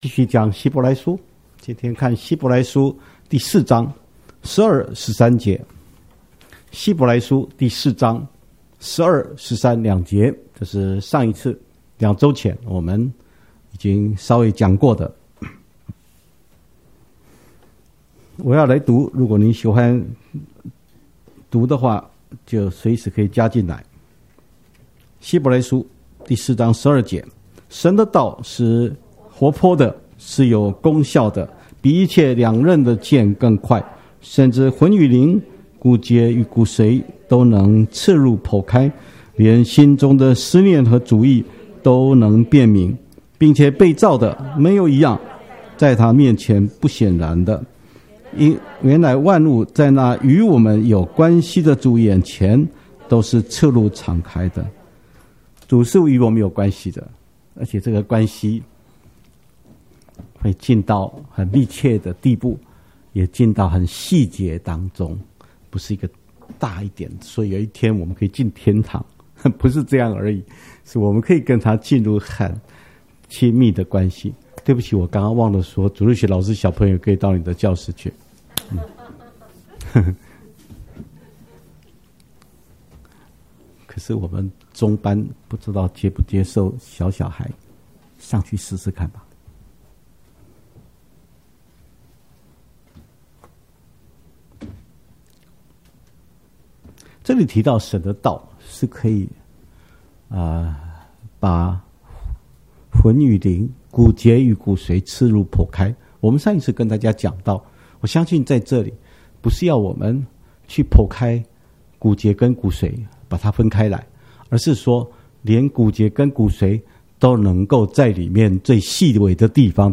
(講道)